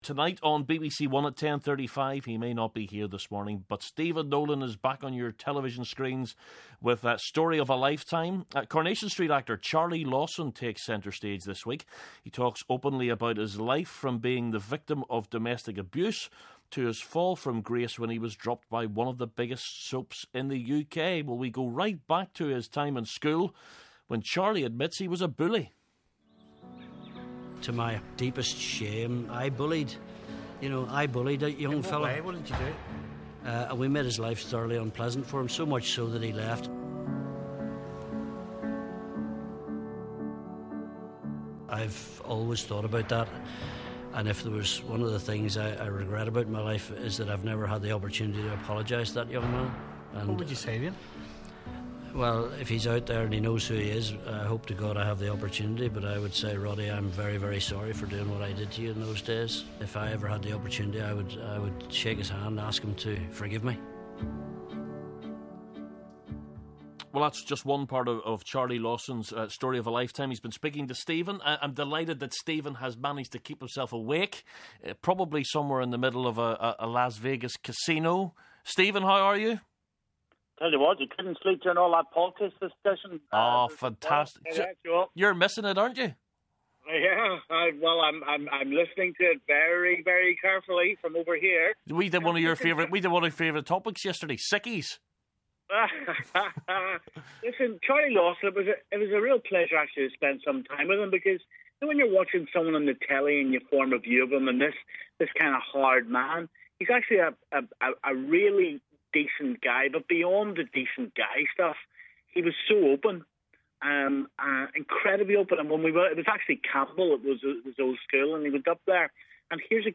Down the line from America!